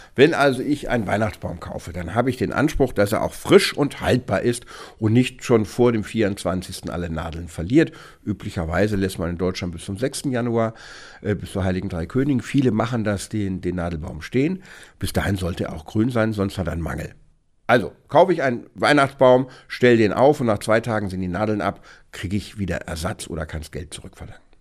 O-Ton: Wenn der Tannenbaum schnell nadelt, kann man umtauschen – Vorabs Medienproduktion